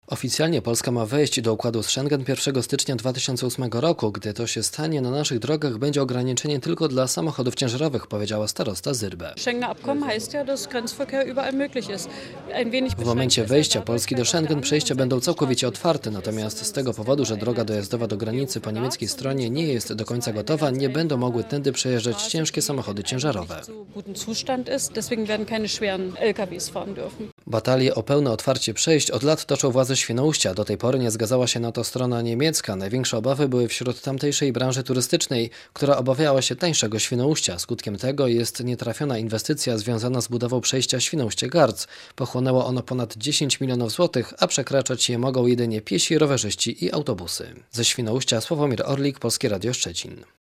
Samochody osobowe będą mogły przejeżdżać przez przejścia graniczne w Świnoujściu, gdy Polska wejdzie do Układu z Shengen - powiedziała dziś Radiu Szczecin Barbara Syrbe, Starosta Powiatu Przedpomorze Wschodnie.